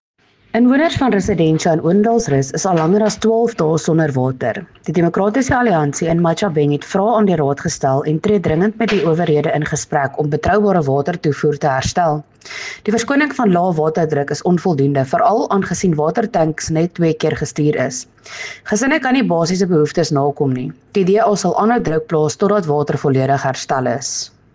Afrikaans soundbites by Cllr Estelle Dansey and Sesotho soundbite by Cllr Kabelo Moreeng.